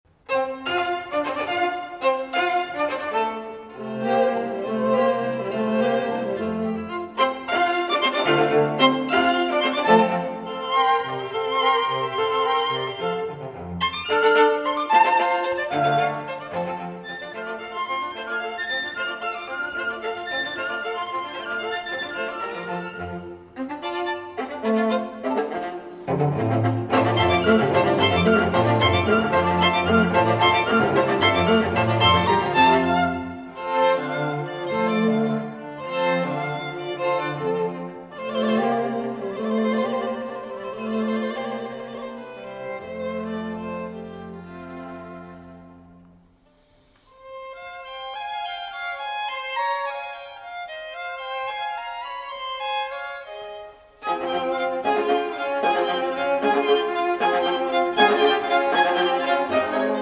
the string quartet